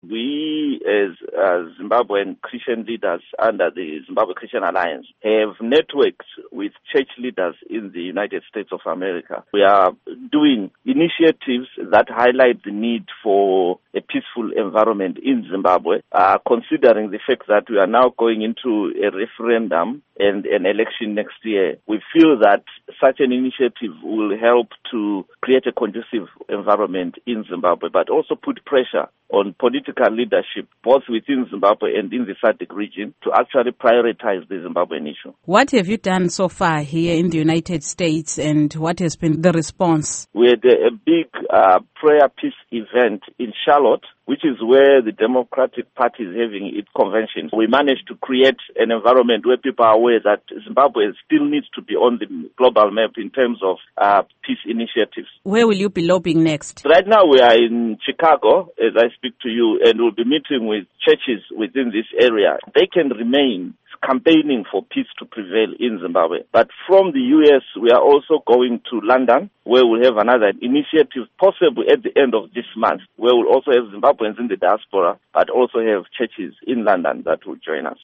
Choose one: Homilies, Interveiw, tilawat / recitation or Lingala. Interveiw